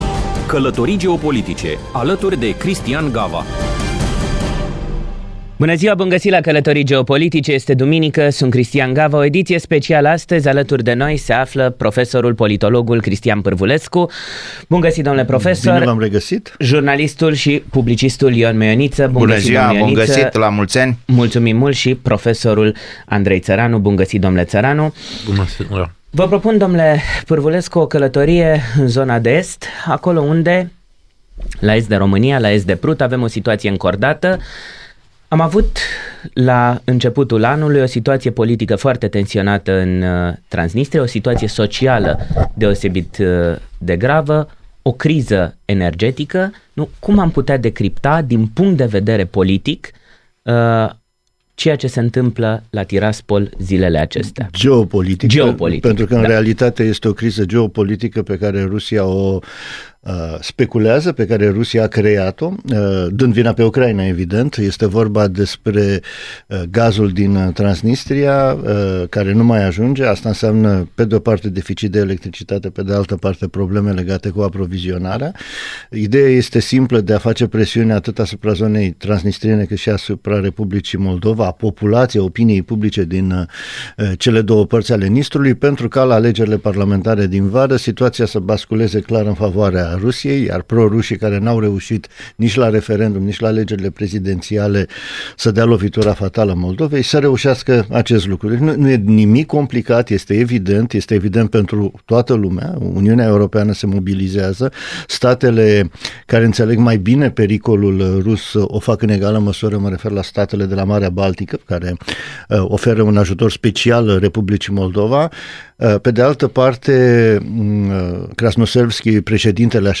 O discuție